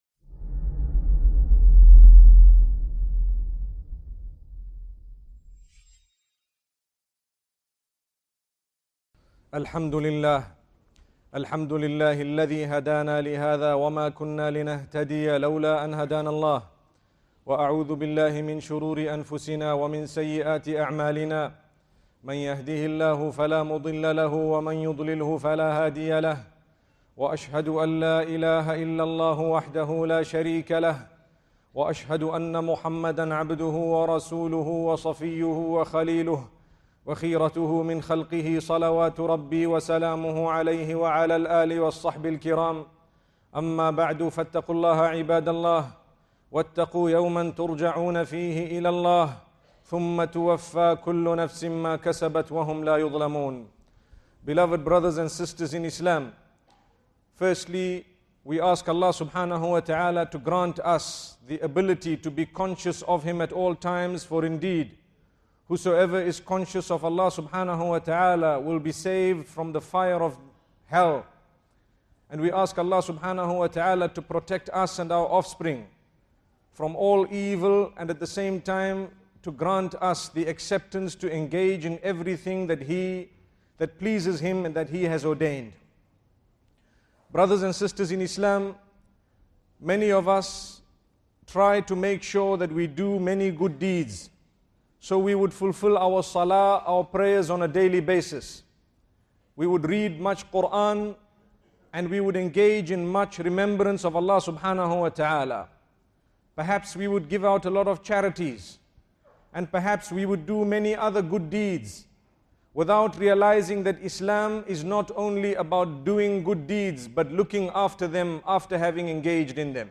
Yet Mufti Ismail Menk, in his powerful lecture delivered at Fanar – Qatar Islamic Cultural Center, issues a sobering reminder: doing good deeds is only half the battle. The greater challenge is protecting those deeds from the relentless assault of Shaytan, who has sworn since the beginning of creation to waylay mankind from every direction.